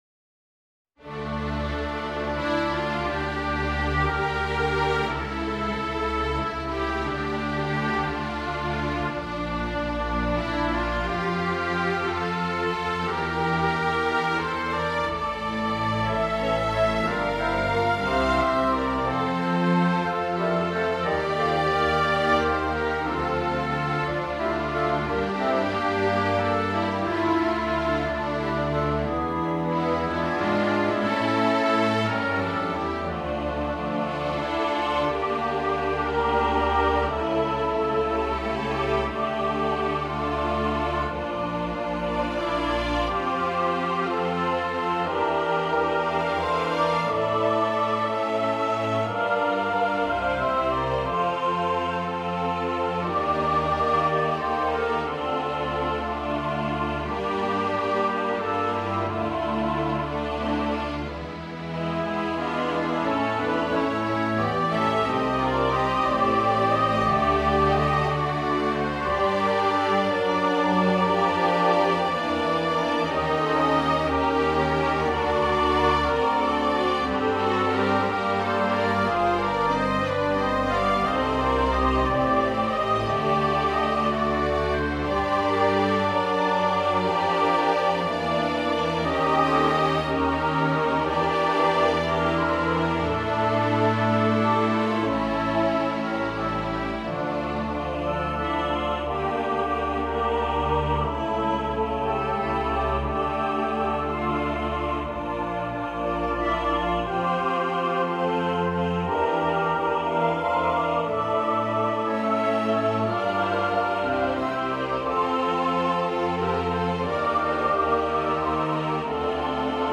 2-Orch-Vom-Verlieren-2.mp3